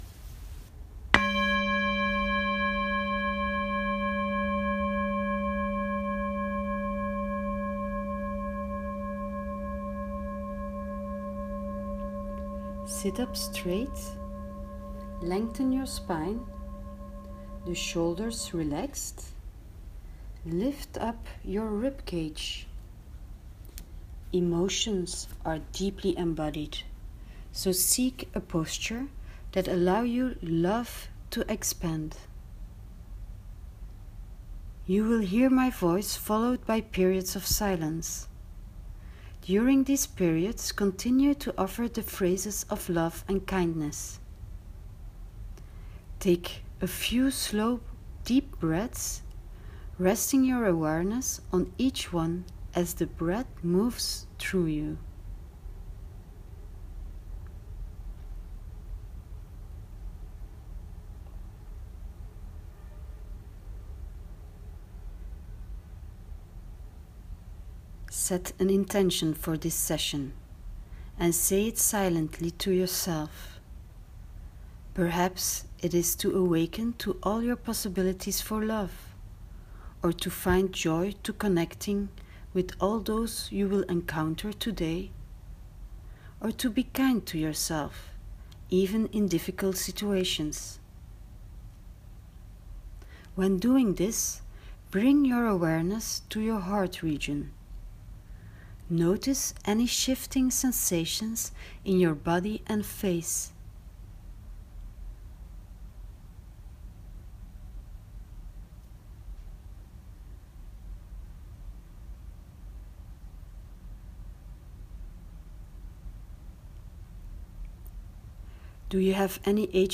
You'll find the guided meditation as a voice recording under the screen with the introduction video.